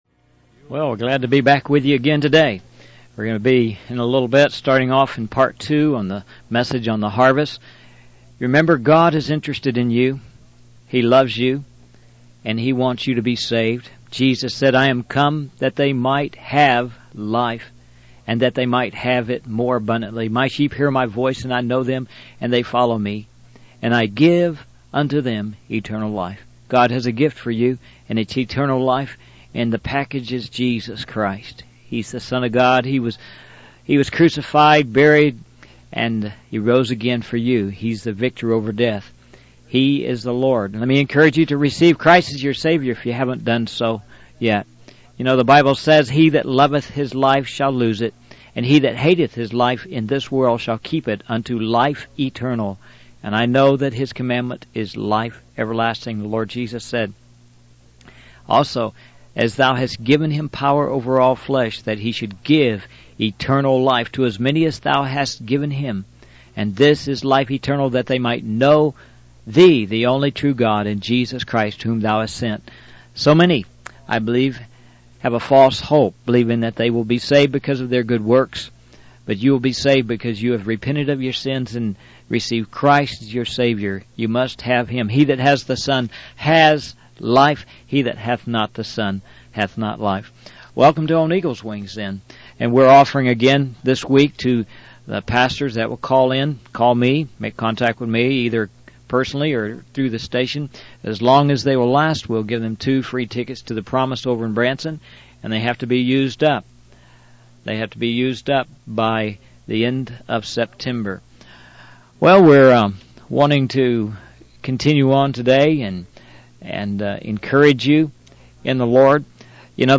In this sermon, the speaker emphasizes the importance of knowing the Lord and being faithful to His will.